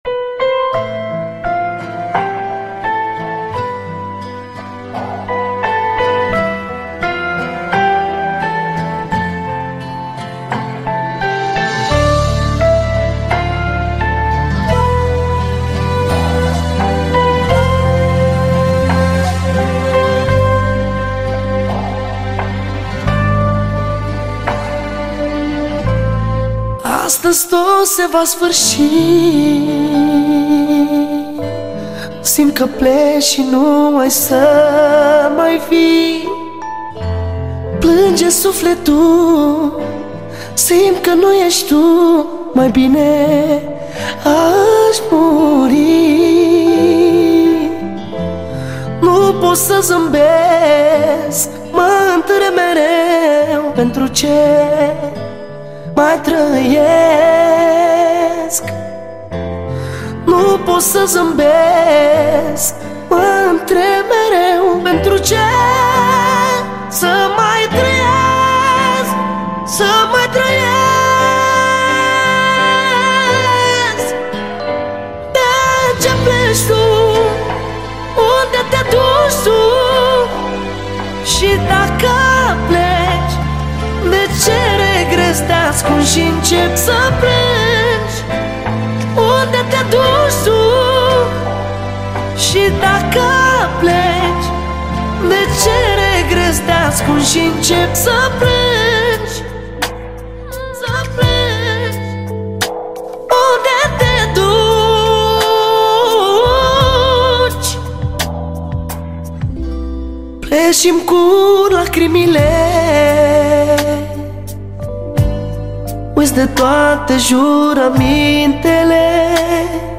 Data: 30.10.2024  Manele New-Live Hits: 0